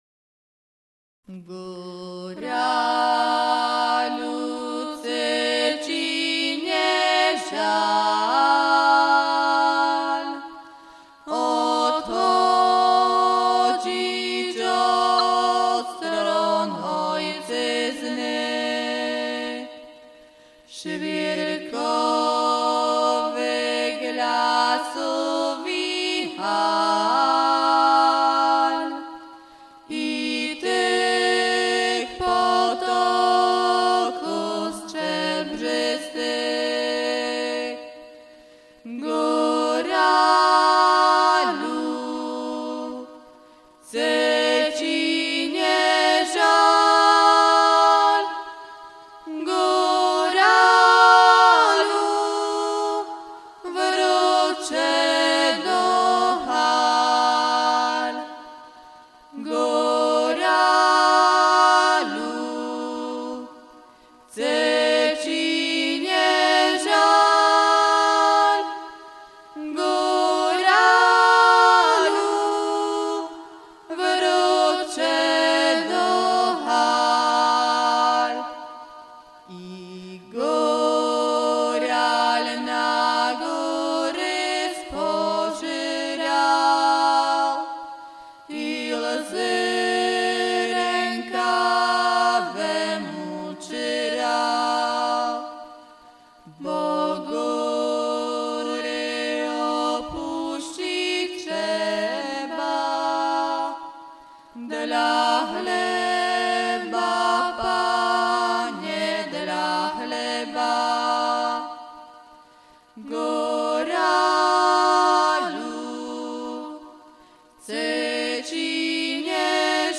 Dosť silnou stránkou súboru je hlavne spevácka zložka.
Súbor má aj vlastnú detskú goralskú muziku, ktorej členovia, tak ako mnohé generácie pred nimi, sú v tejto oblasti "samoukmi" a svoje znalosti získavajú hlavne posluchom a "odkukávaním" techniky od starších muzikantov.
Detská goralská muzika (1998)